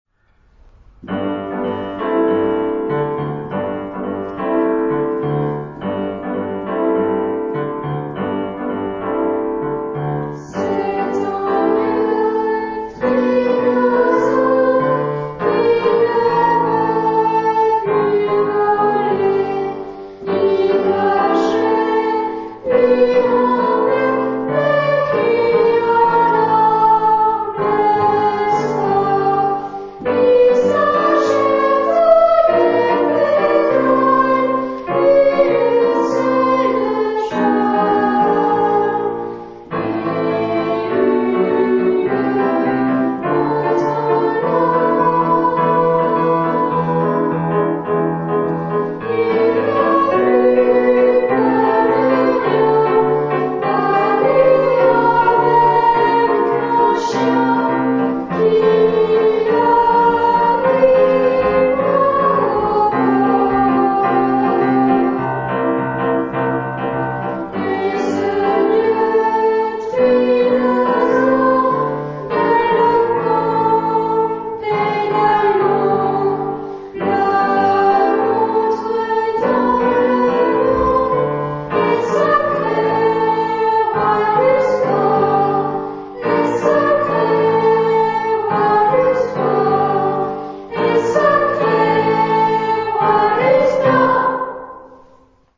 Tonart(en): C (tonales Zentrum um)